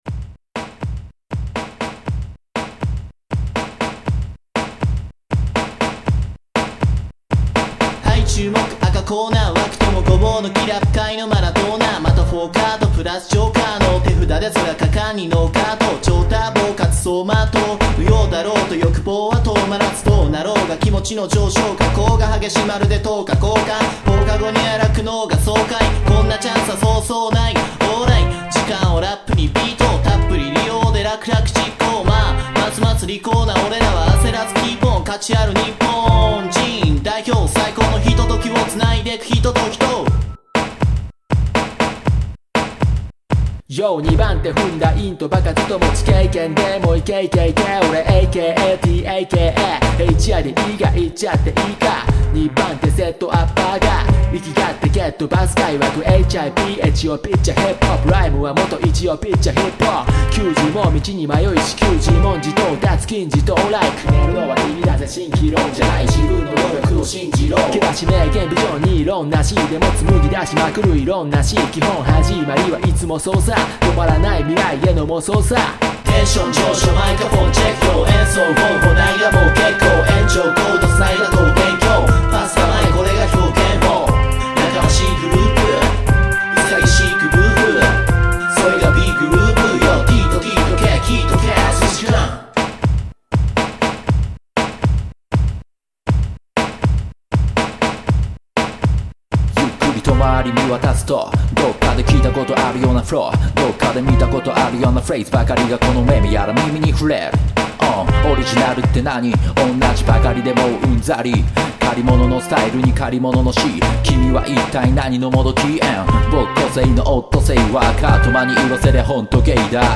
◆ライブ用に作られた曲。